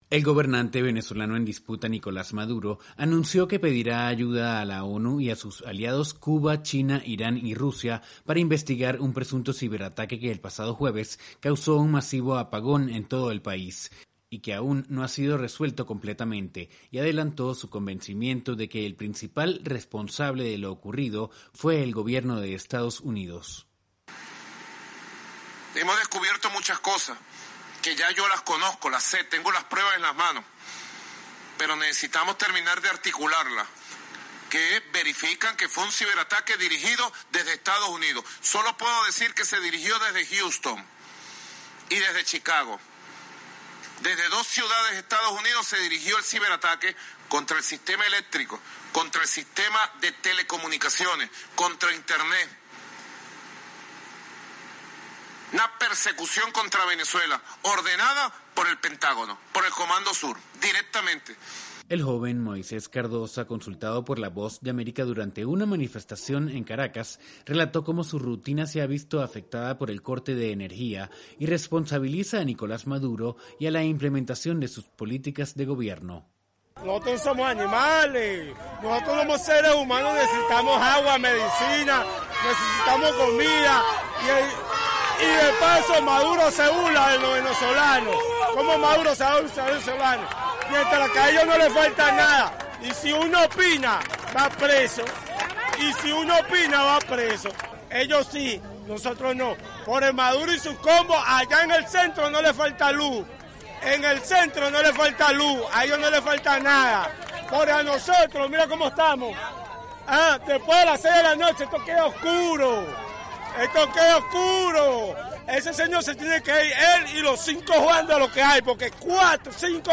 VOA: Informe desde Venezuela